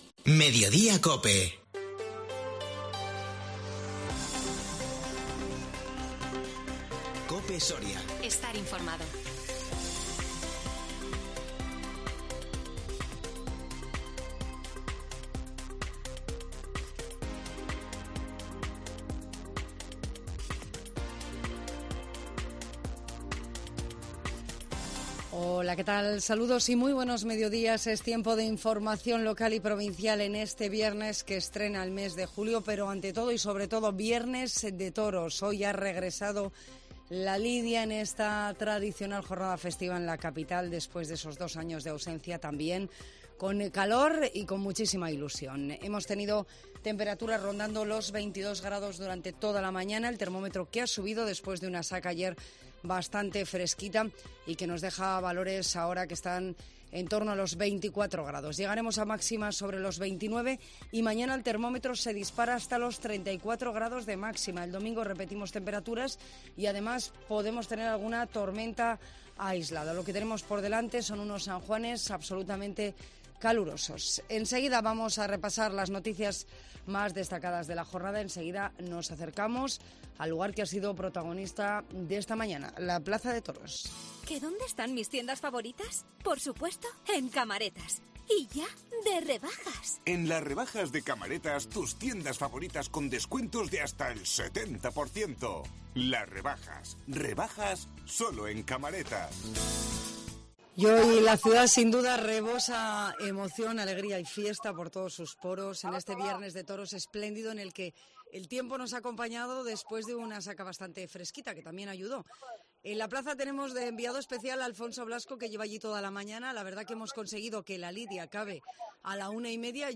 INFORMATIVO MEDIODÍA COPE SORIA 1 JULIO 2022